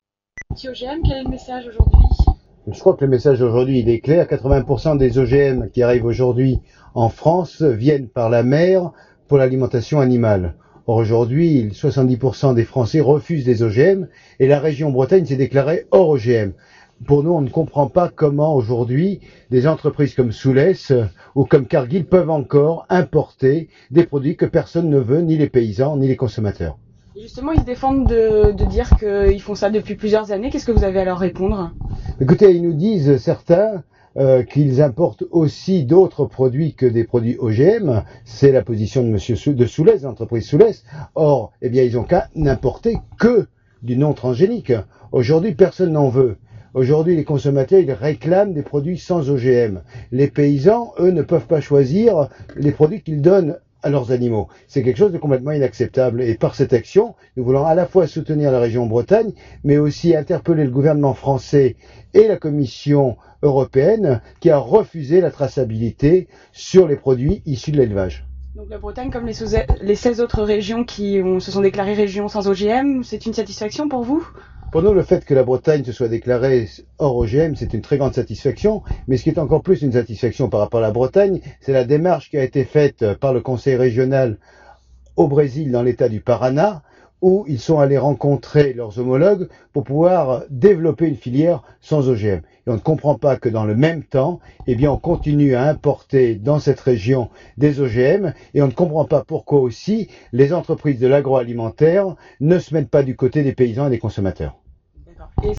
INTERVIEW DE JOSE BOVE :
Jeudi soir, ls militants de l’Esperanza avaient débarqué sur une petite plage à l’ouest de Lorient.
LORIENT - INTERVIEW J. BOVE - mp3-1600k